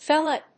/ˈfelɑ(米国英語), ˈfelʌ(英国英語)/